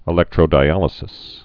(ĭ-lĕktrō-dī-ălĭ-sĭs)